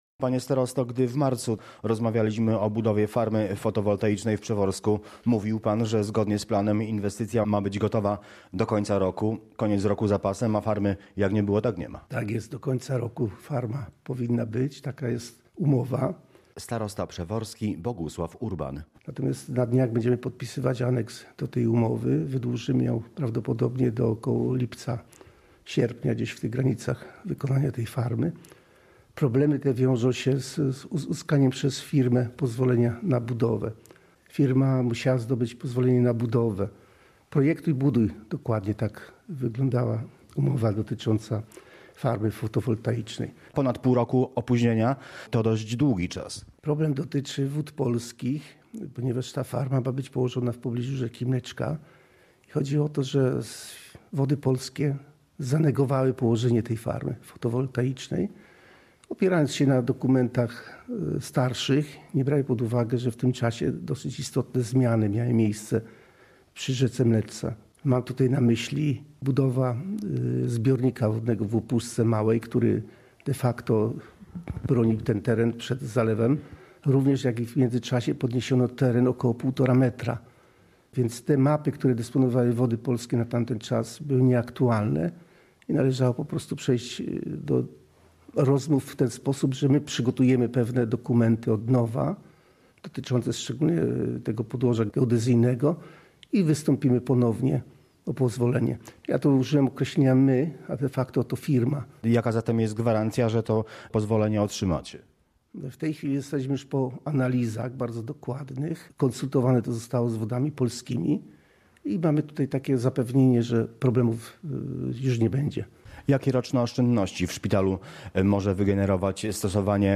Dokumenty zostały już uzupełnione i przesłane przez wykonawcę do Wód Polskich – zapewnia wicestarosta przeworski Jacek Kierepka.
Relacja: